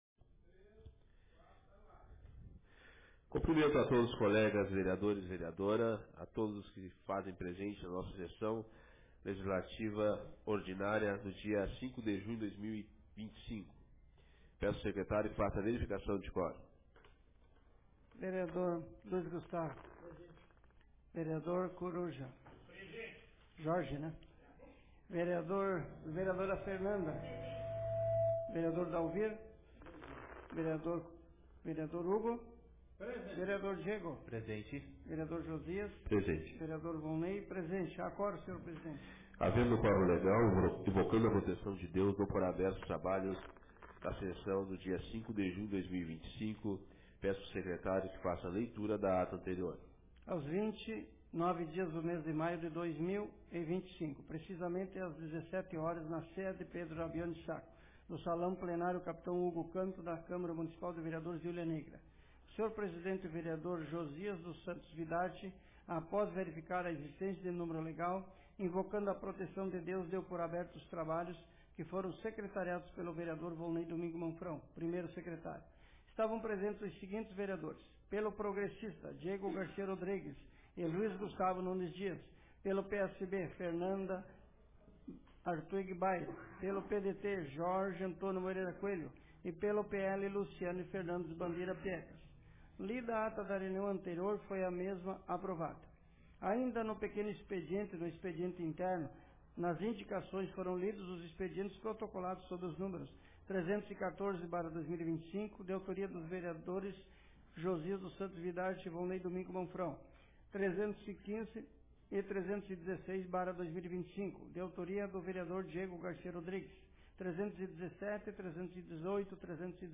Sessão Ordinária da Câmara de Vereadores de Hulha Negra Data: 05 de junho de 2025